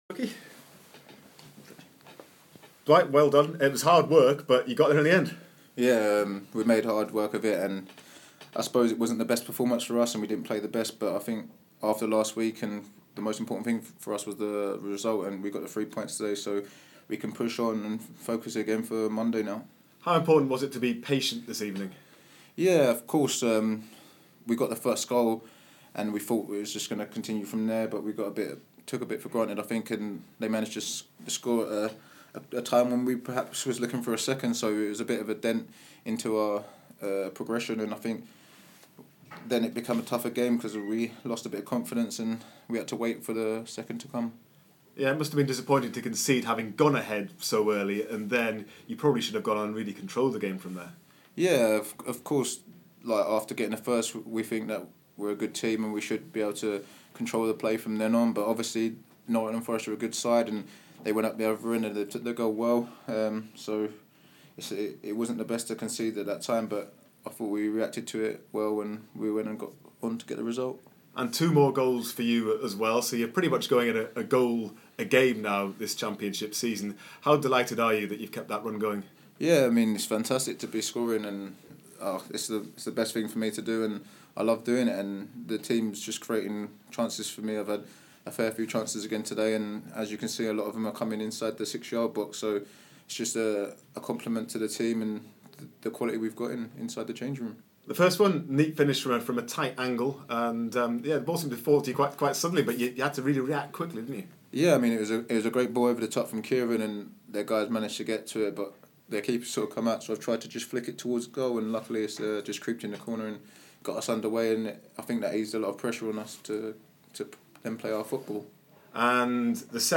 Dwight Gayle chatted to BBC Newcastle after scoring twice in the Magpies' 3-1 win against Nottingham Forest.